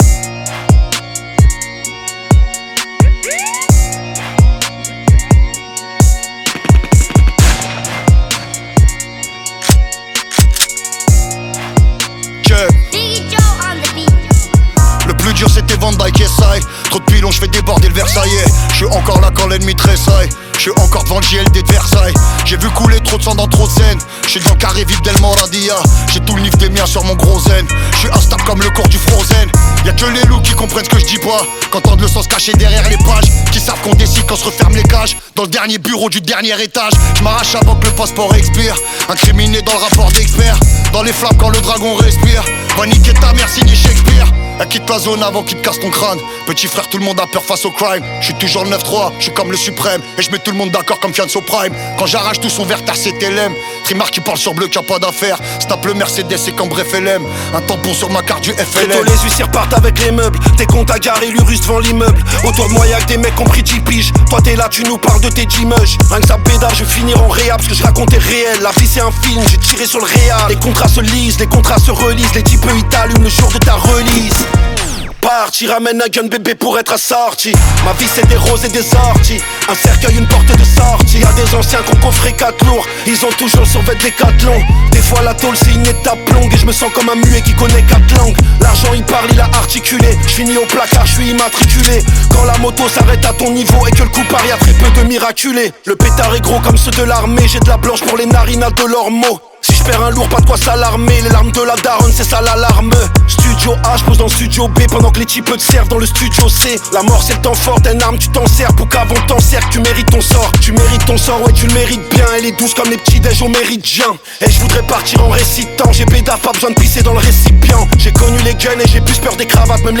Genres : french rap, french r&b